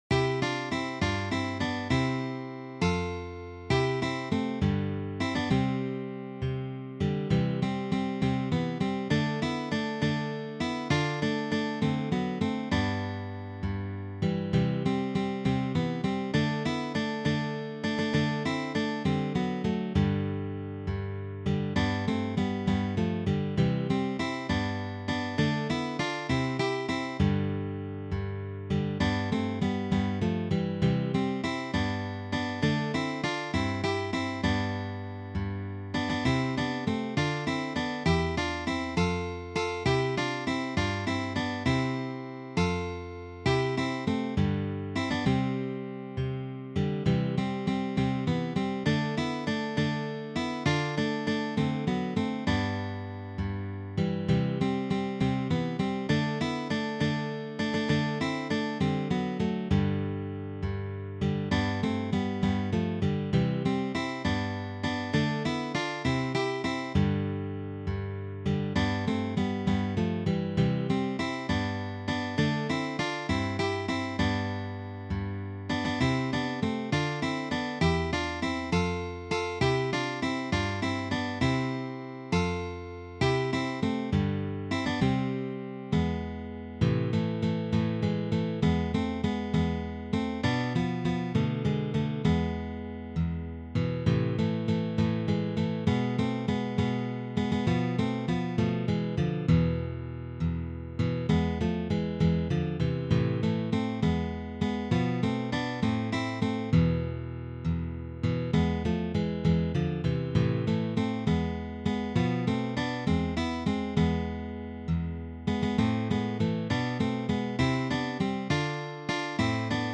arranged for three guitars
for three guitars